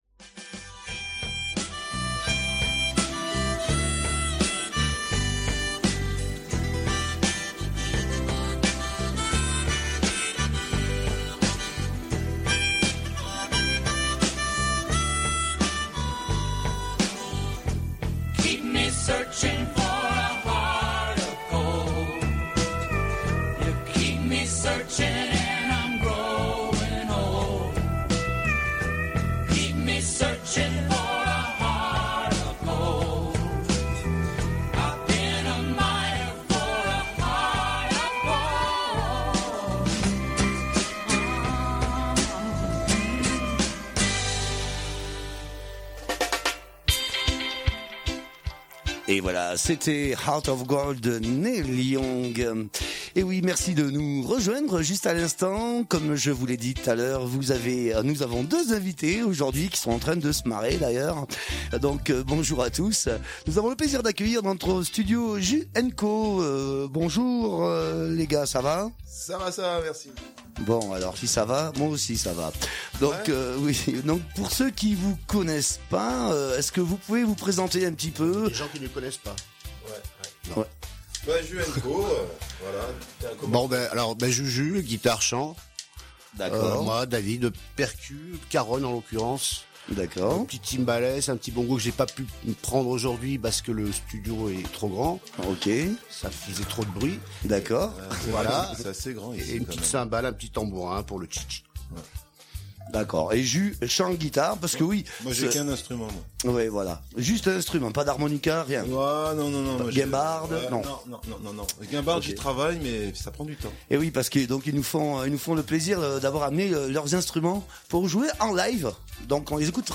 Nous avons le plaisir d'accueillir aujourd'hui " Ju and Co " dans notre studio.